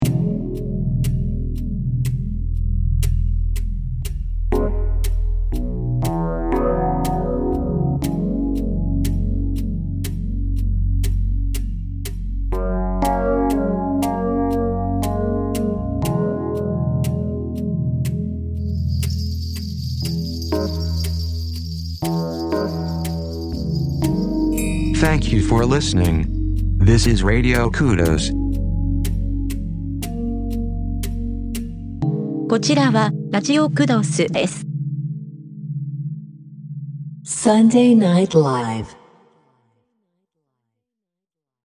曲のキーが初代ジングルと違うので ちょっとだけ印象が変わった かも知れませんね